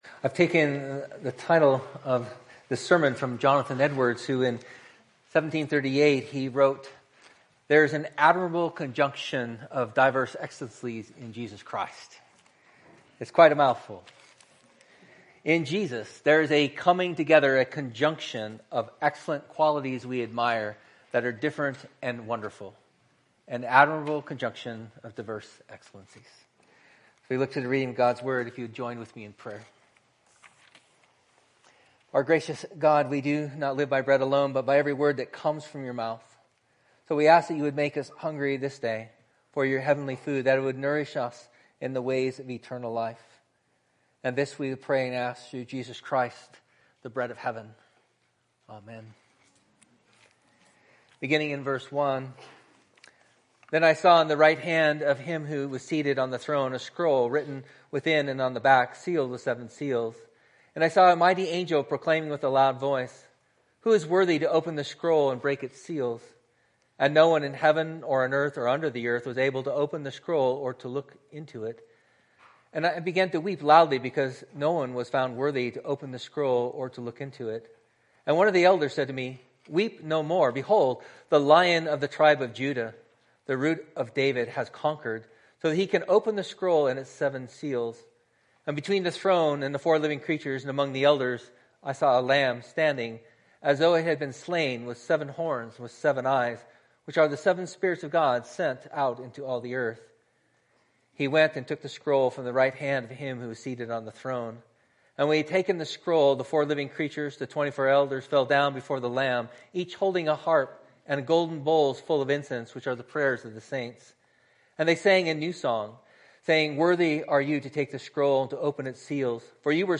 I’ve taken the title of the sermon from Jonathan Edwards, who in 1738, he wrote, There’s an admirable conjunction of diverse excellencies in Jesus Christ.